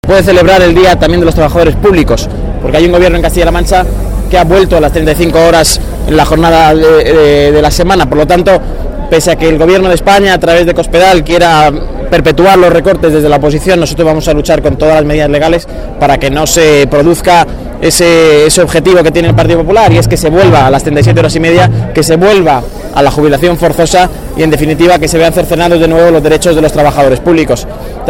Portavoz: Empleados públicos